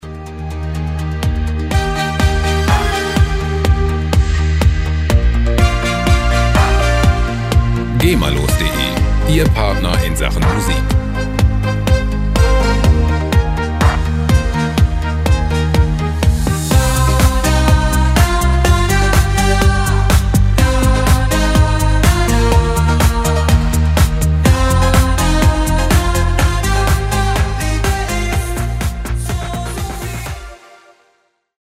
Gema-freie Schlager
Musikstil: Pop Schlager
Tempo: 124 bpm